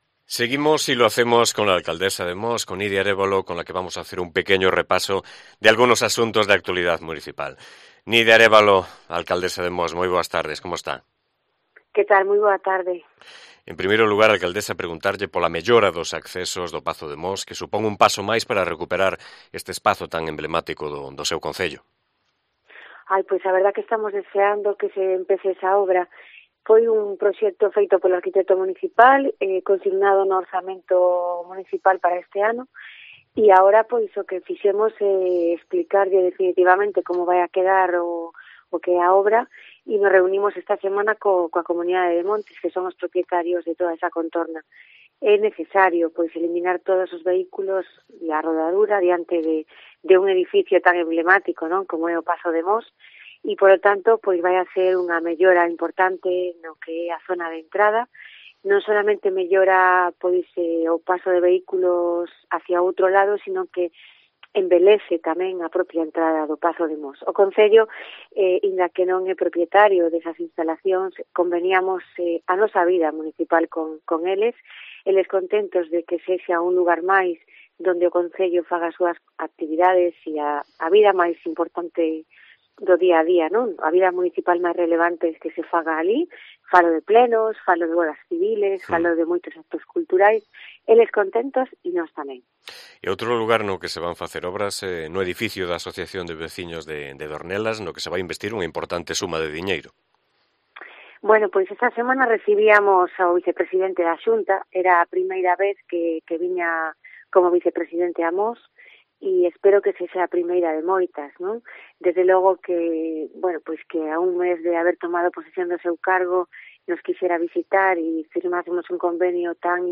Entrevista con Nidia Arévalo, alcaldesa de Mos